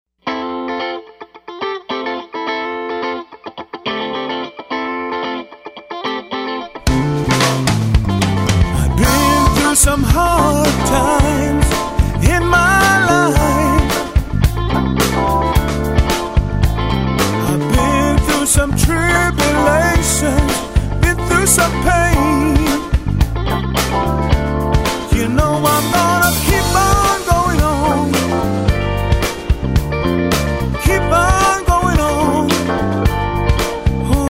2007's Top Native Gospel Album